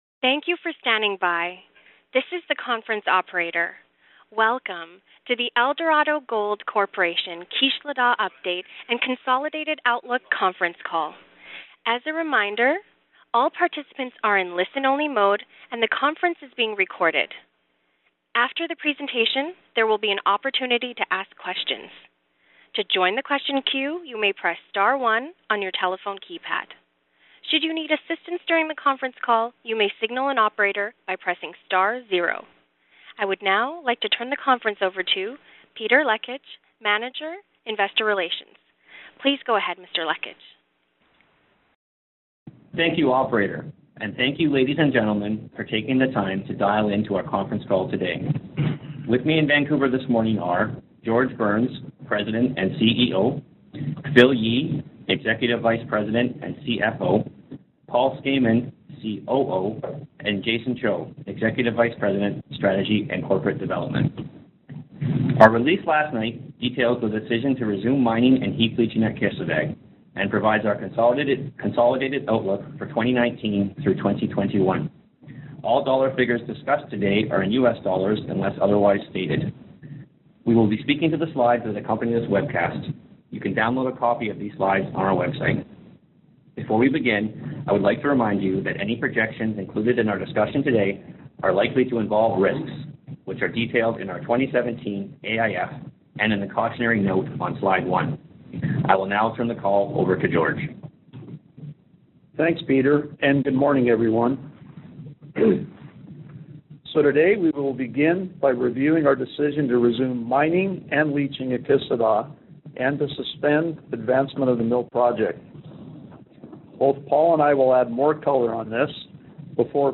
Eldorado Gold’s Kisladag Update & Consolidated Outlook (2019-2021) Conference Call and Webcast